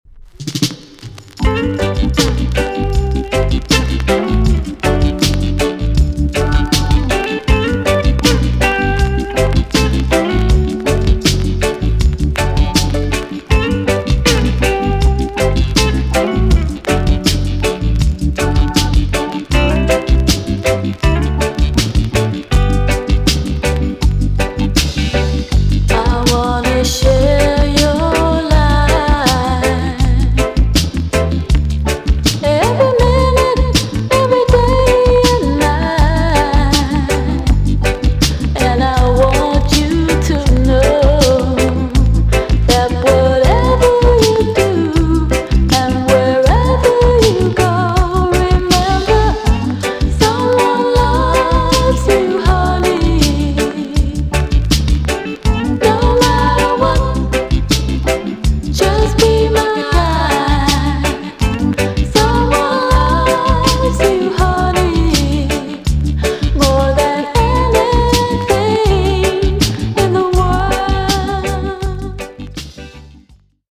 VG+ 少し軽いチリノイズが入りますが良好です。
BIG TUNE!!WICKED LOVERS TUNE!!